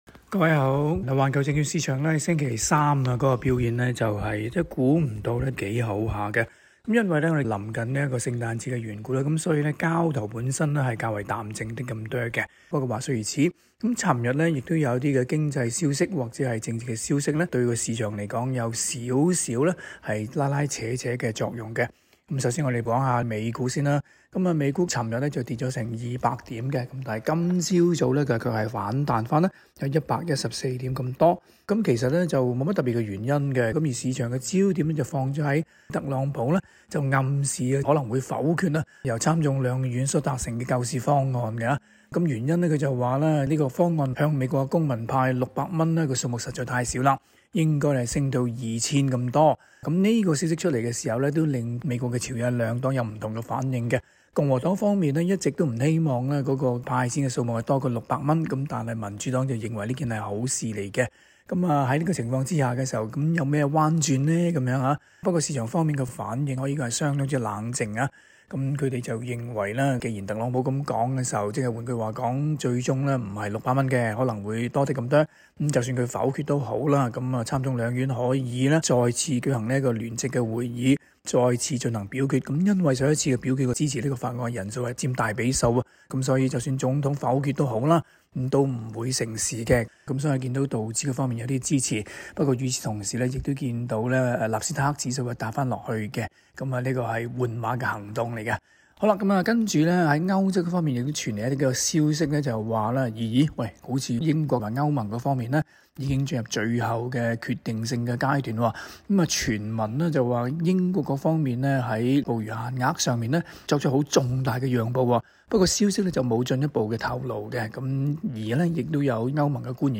今期【中港快訊】環節為大家重點報道特首昨日公佈明年疫苗接種計劃的詳情，究竟内容如何？